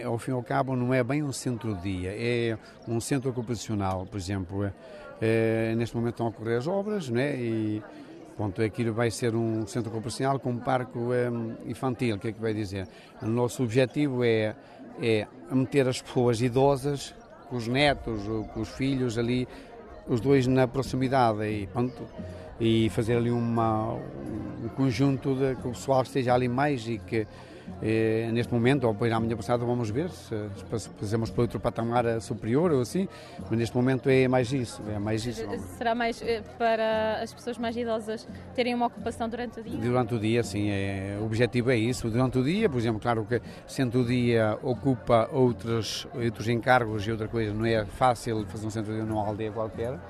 A antiga escola primária de Bornes já está a ser transformada num centro ocupacional, que será um espaço destinado aos mais idosos e às crianças. O edifício localizado na aldeia do concelho de Macedo de Cavaleiros terá uma função intergeracional, como destacou Júlio Quintela, presidente da junta de Freguesia de Bornes e Burga:
Declarações à margem da VIII edição da feira da Cereja.